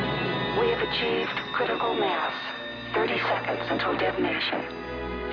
And the computer's verbal warning is accompanied by the sound of an old-fashioned bell ringing! Kind of a low tech sound for the Visitors to use isn't it?
bell.wav